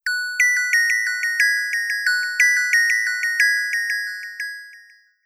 notification_one.wav